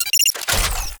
unlock.ogg